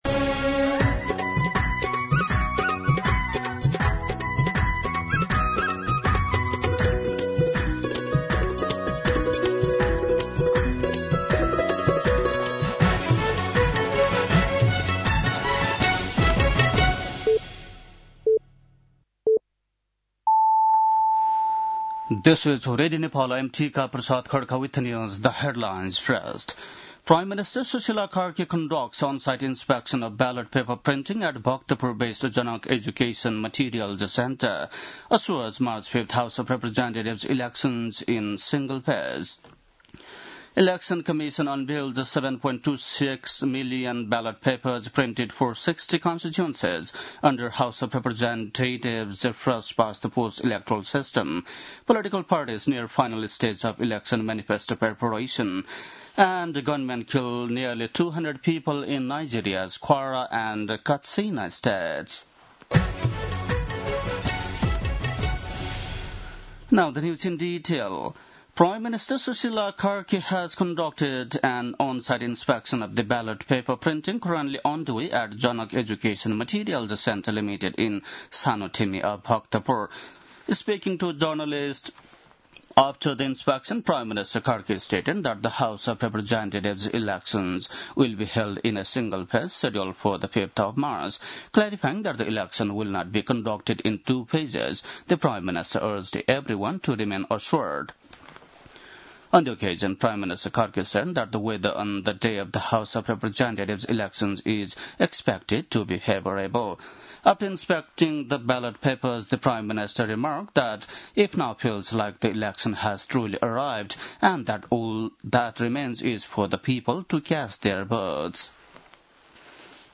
An online outlet of Nepal's national radio broadcaster
बेलुकी ८ बजेको अङ्ग्रेजी समाचार : २२ माघ , २०८२
8-pm-news.mp3